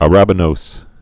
(ə-răbə-nōs, ărə-bə-)